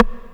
RIM 12    -L.wav